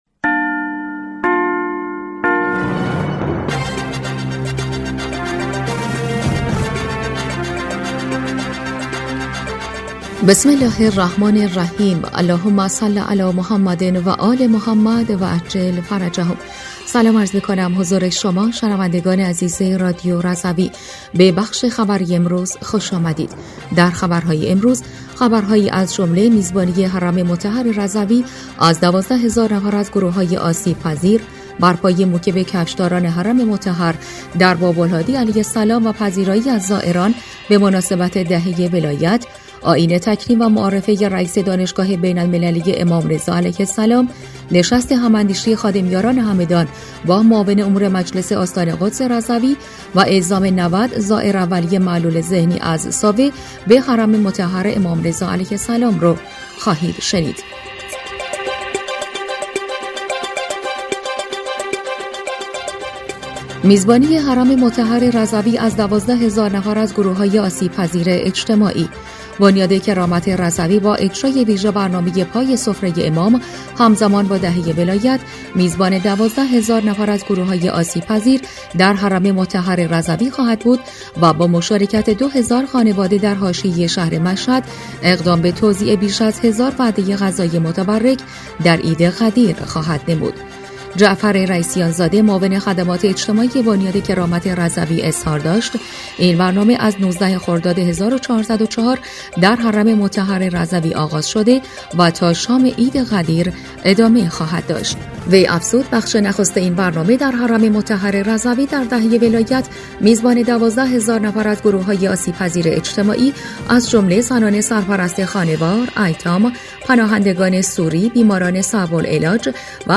بسته خبری چهارشنبه ۲۱ خردادماه ۱۴۰۴ رادیو رضوی؛